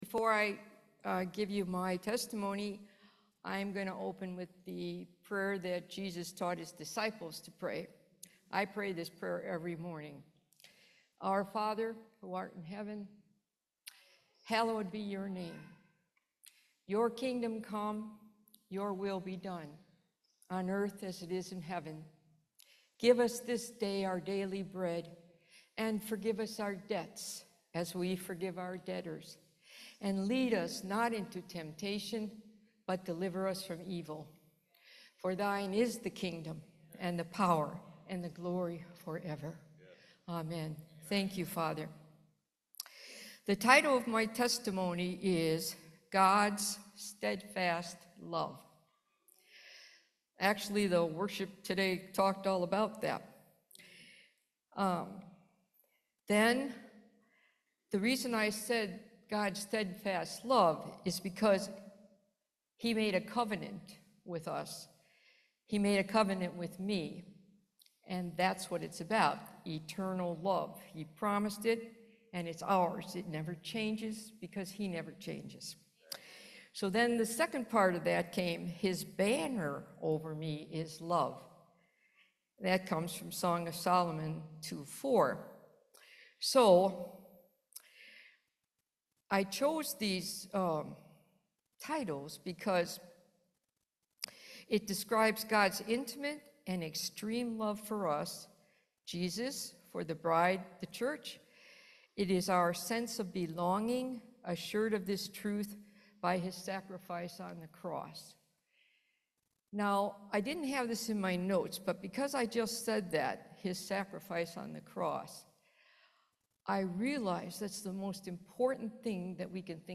Series: Testimony
Service Type: Main Service